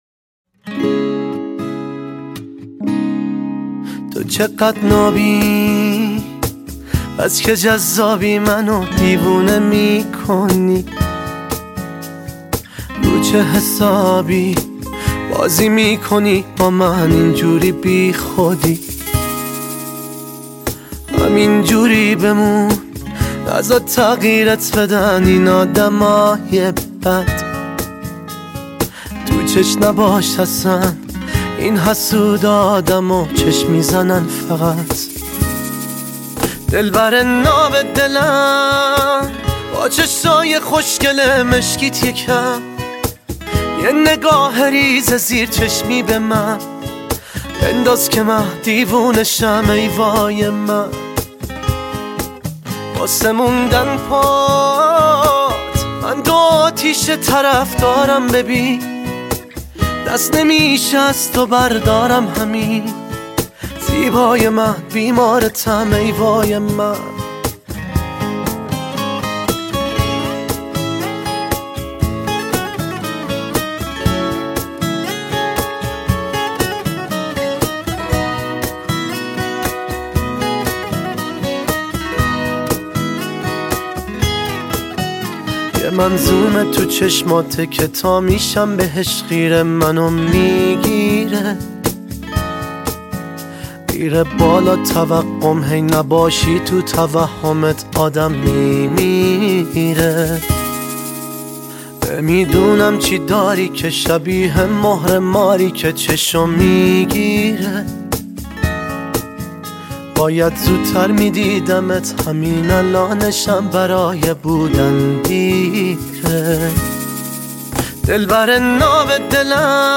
ایول به صدای گرمت.